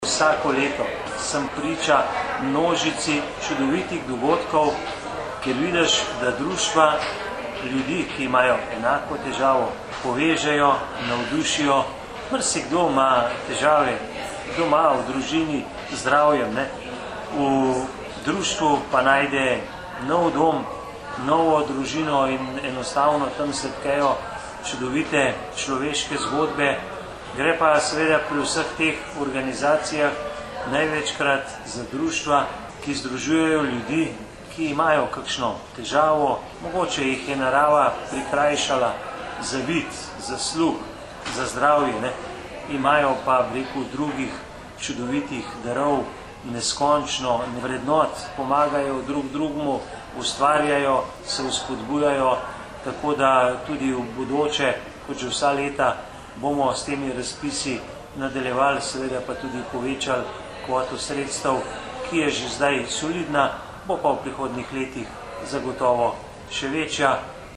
95229_izjava_zupanobcinetrzicmag.borutsajovicohumanitarnihdrustvih.mp3